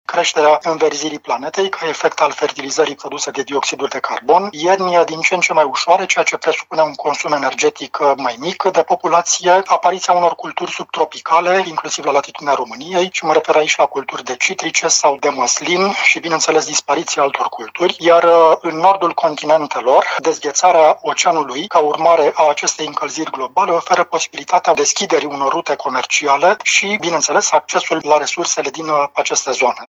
Este rezultatul schimbărilor climatice, cauzate în principal de poluare, susține specialistul, care a enumerat și câteva dintre efectele pozitive ale acestor schimbări: